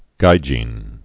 (gījēn, -jĭn)